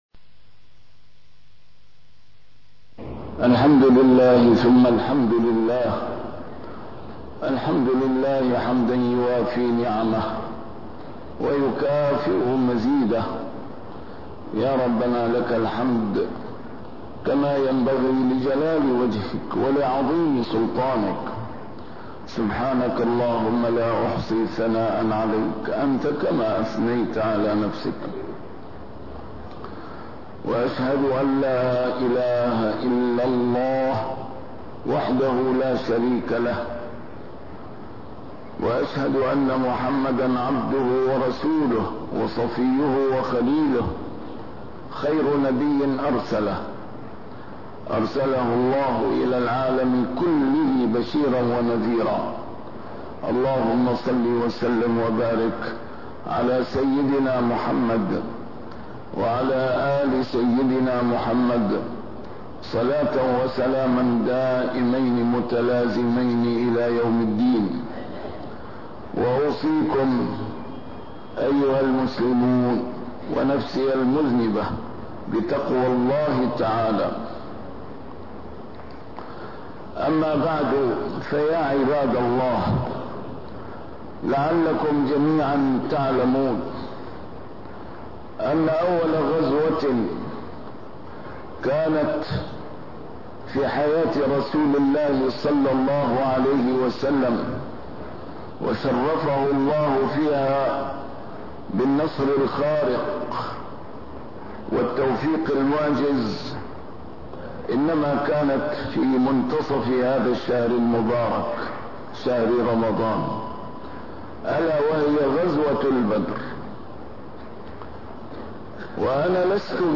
A MARTYR SCHOLAR: IMAM MUHAMMAD SAEED RAMADAN AL-BOUTI - الخطب - سرّ النصر الالتجاء إلى الله وأول المكلفين بذلك قادة المسلمين